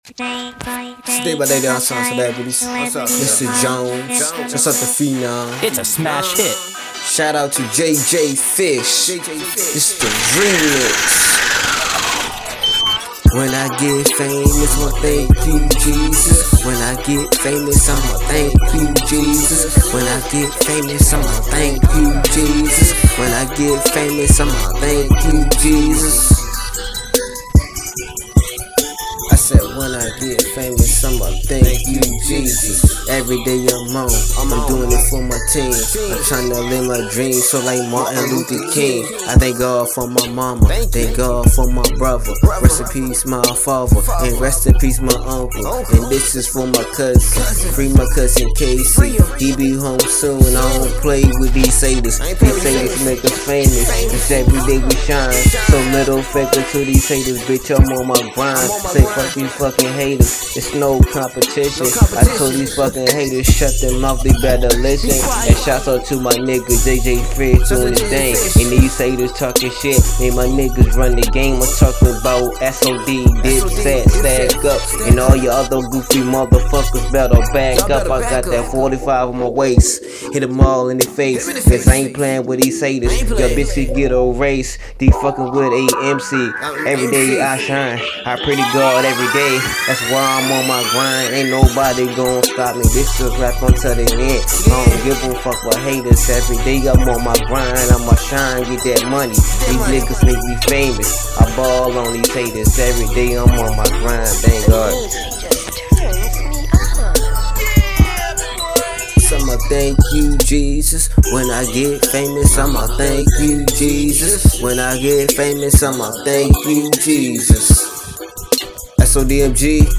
Hiphop
instrumental remake